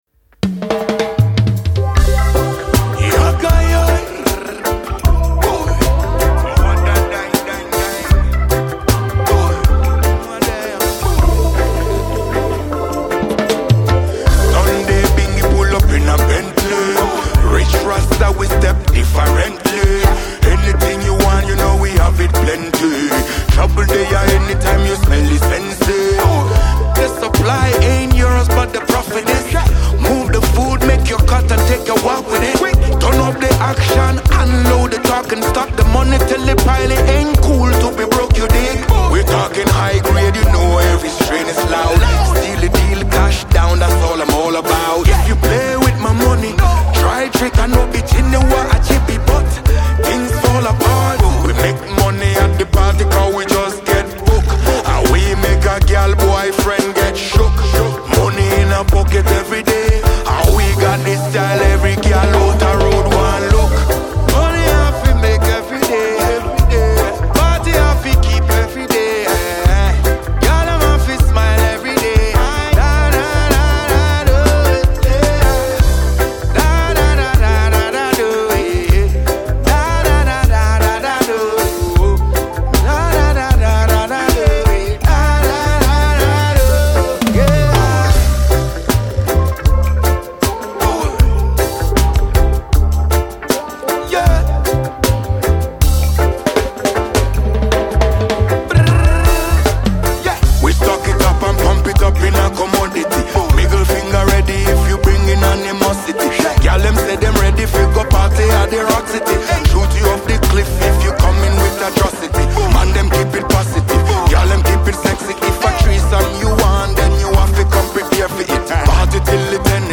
Sensational reggae dancehall act